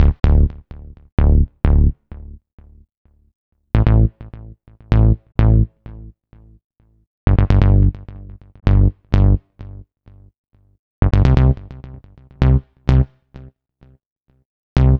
Session 11 - Bass 04.wav